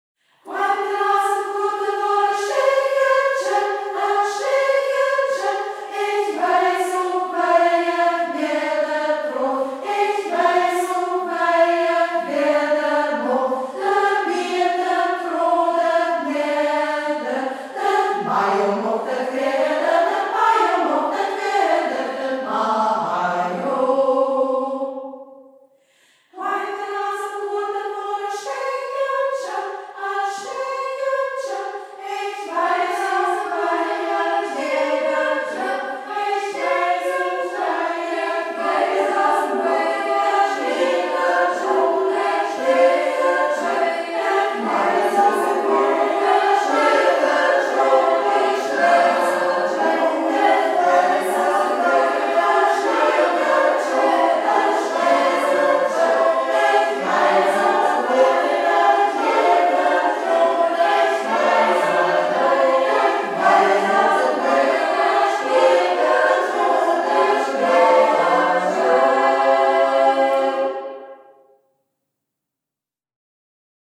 Singkreis Kampestweinkel • Ortsmundart: Braller • 1:13 Minuten • Herunterladen